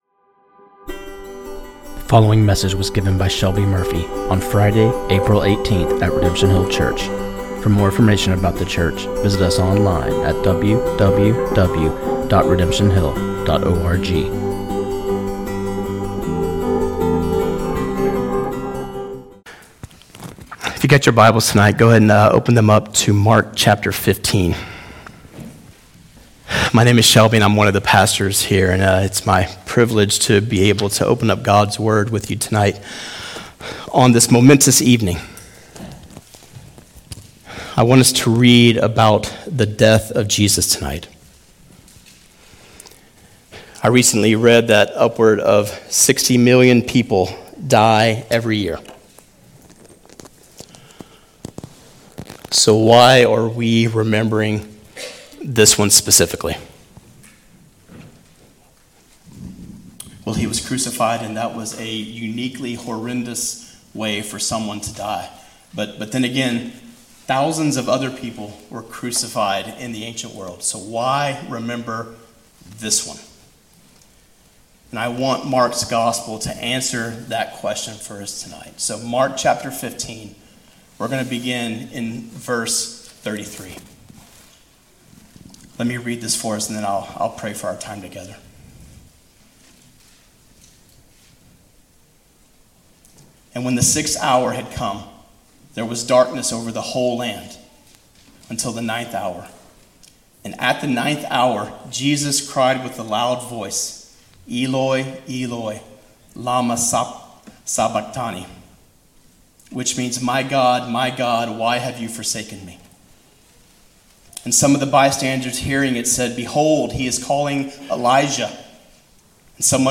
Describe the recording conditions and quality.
at the Good Friday service of Redemption Hill Church